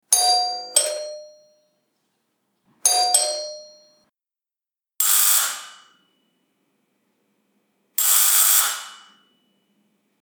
Download Door Bell sound effect for free.
Door Bell